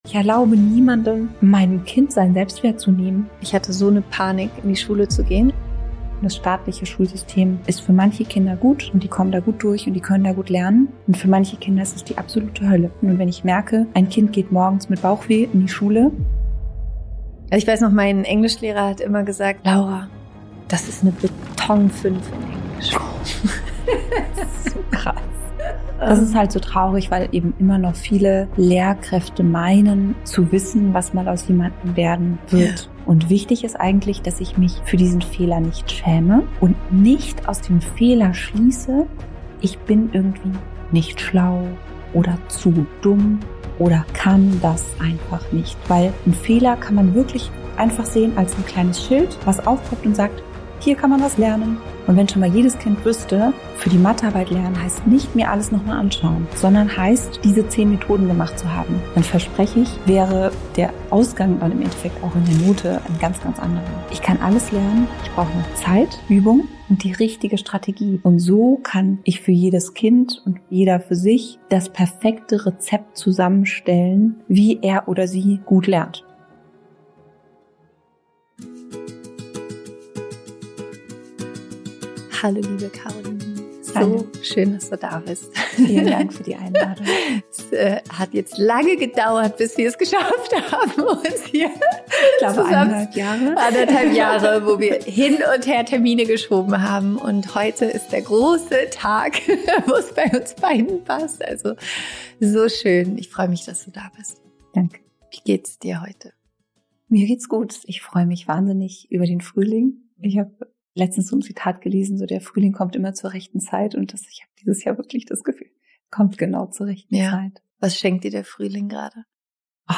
In dieser Podcastfolge spreche ich mit Bildungscoachin